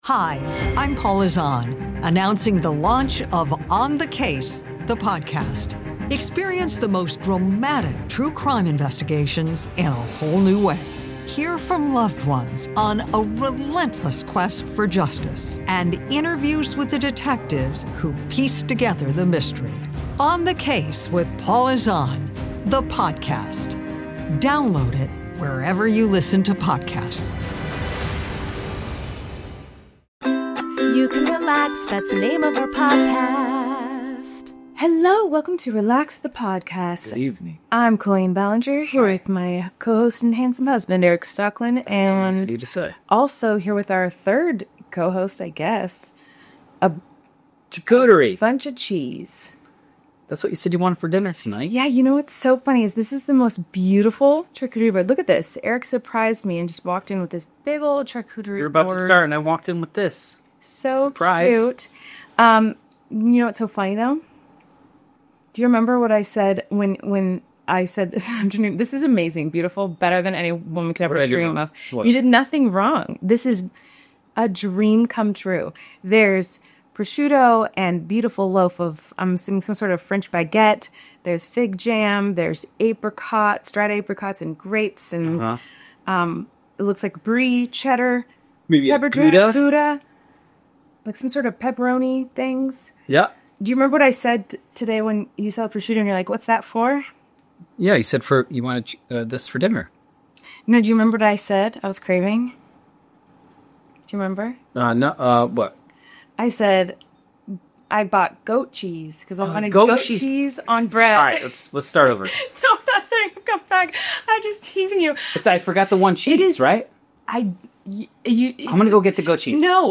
So sorry the sound is messed up! In true "Relax Podcast" fashion, we had technical difficulties with the microphone audio and had to use the camera audio. We are aware that there is a beeping sound coming from the camera and it's pretty obnoxious.